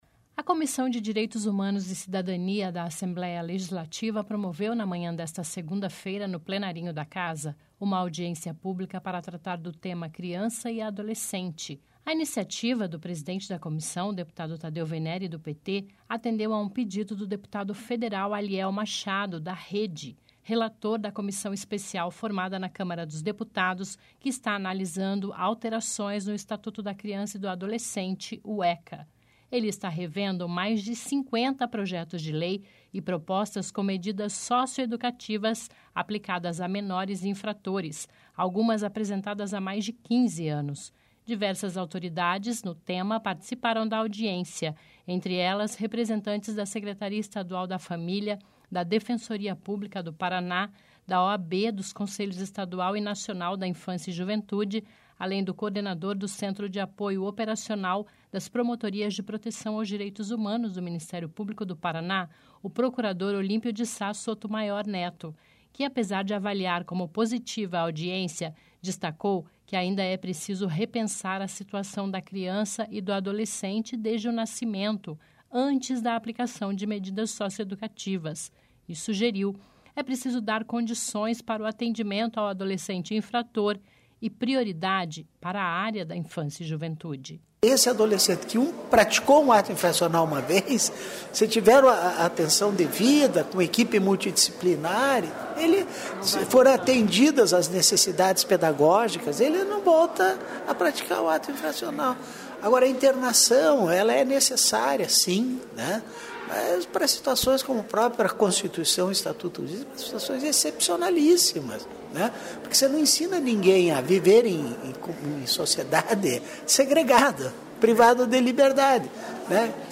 Relator de Comissão Especial da Câmara sobre o ECA ouve autoridades durante audiência